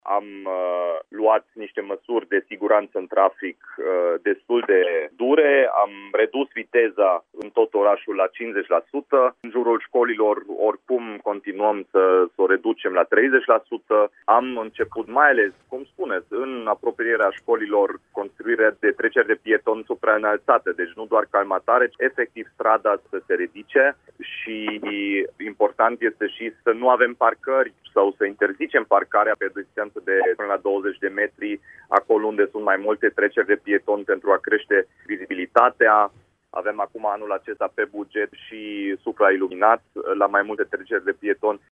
Primarul Dominic Fritz, a precizat, în direct la Radio Timișoara, că vor fi luate măsuri.